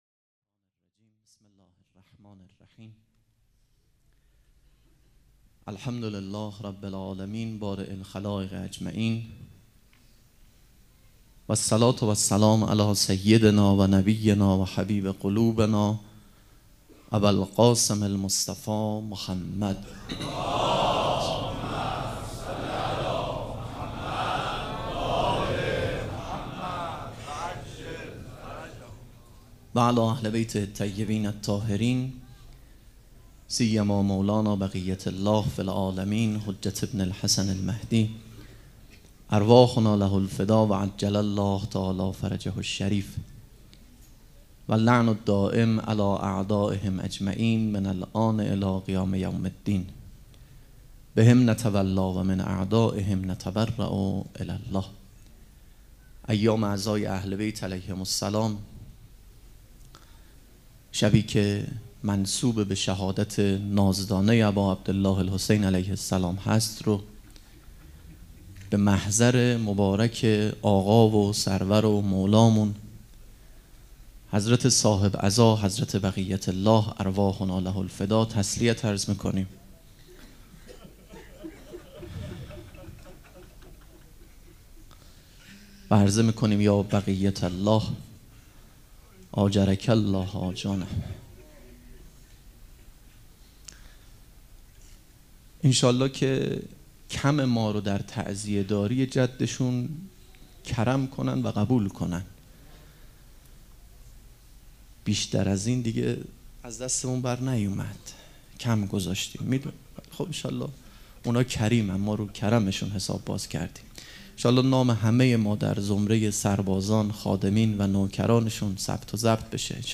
سخنرانی
مراسم عزاداری شب سوم
sokhanrani.mp3